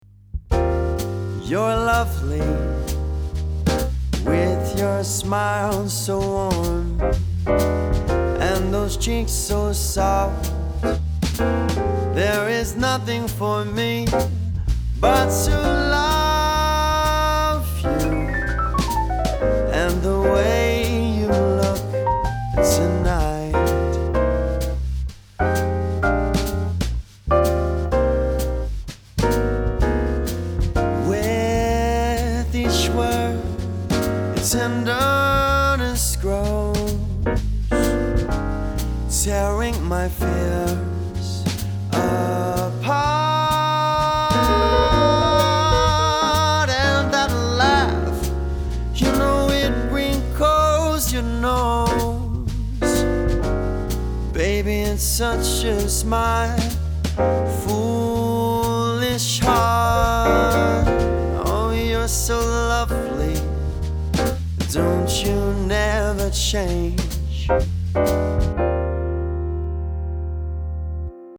Vocals | Drums | Bass | Keyboards